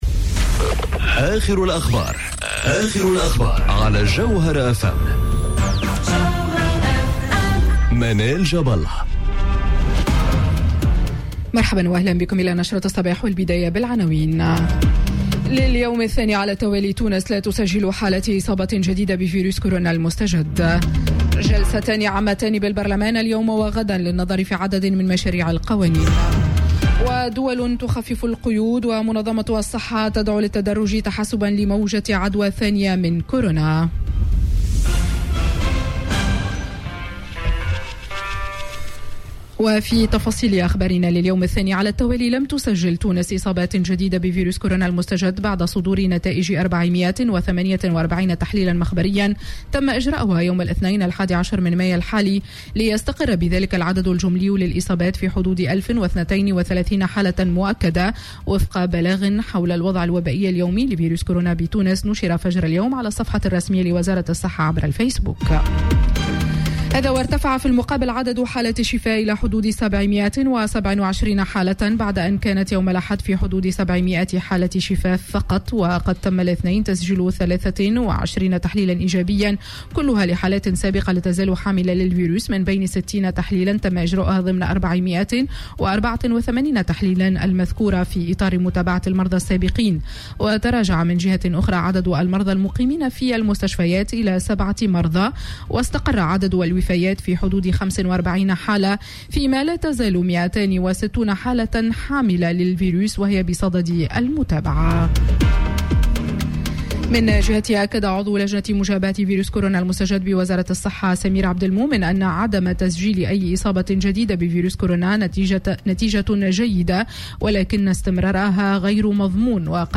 نشرة أخبار السابعة صباحا ليوم الثلاثاء 12 ماي 2020